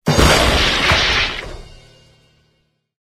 wood_joint_break_05.ogg